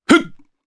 Baudouin-Vox_Jump_jp.wav